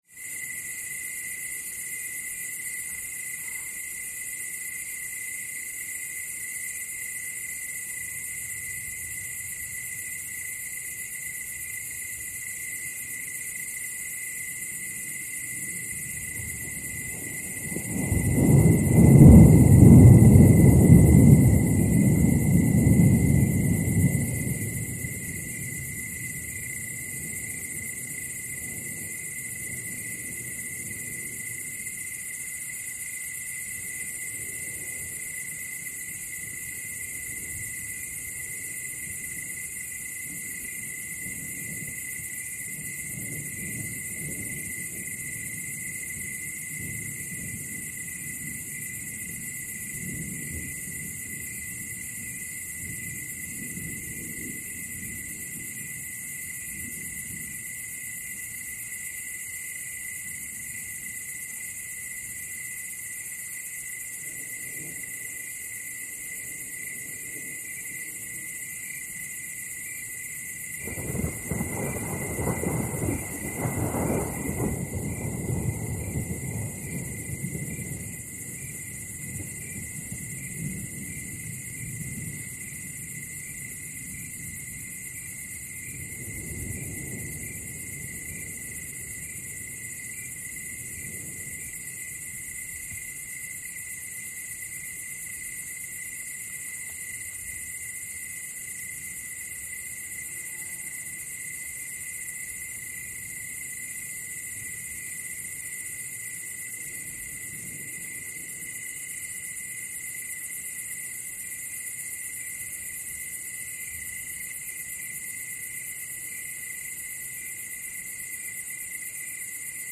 ANIMALS-BIRD BGS AFRICA: Dawn dense close and distant bird calls, insects buzz bys, Luangwa Valley. Zambian atmosphere.